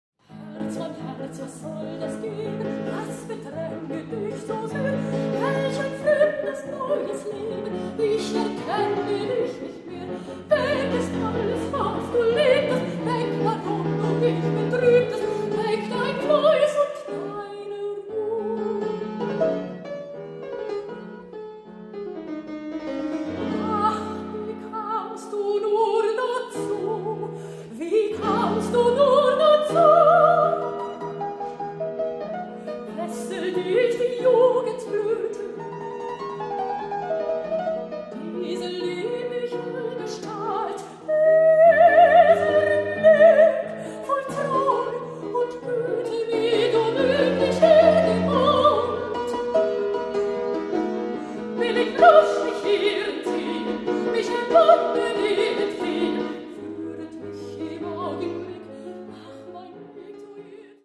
Mezzosopran
Hammerflügel